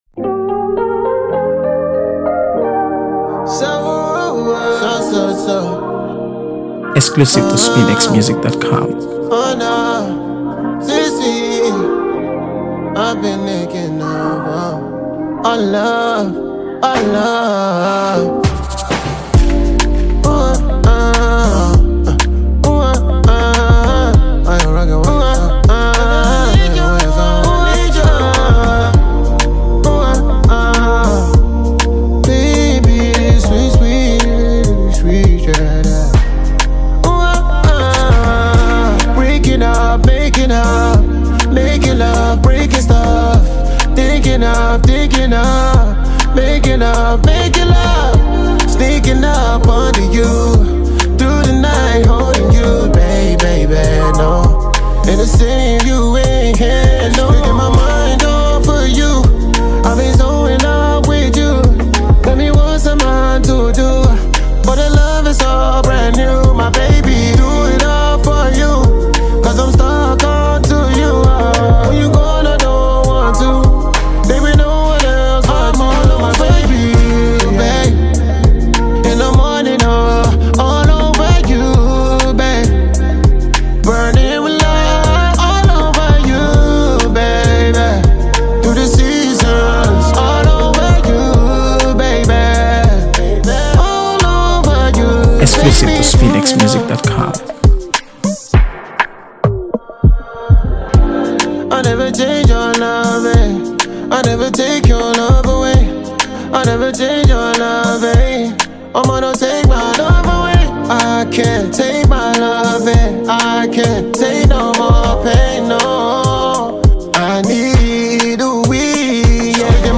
AfroBeats | AfroBeats songs
is a smooth, feel-good track
Afro-fusion